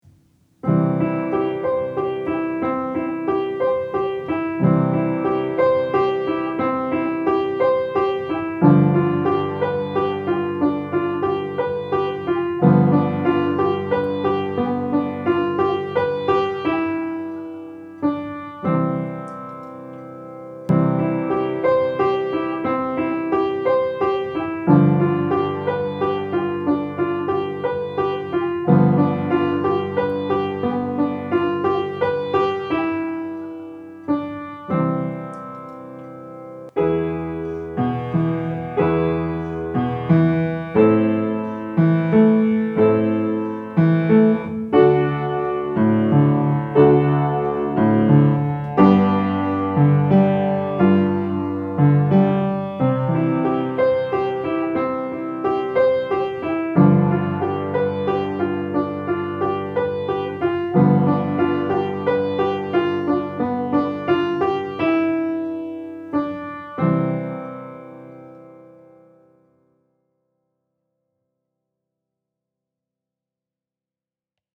Acompañamento:
Esta é unha canción de berce: emprégase para durmir os bebés, con sons doces e tranquilos.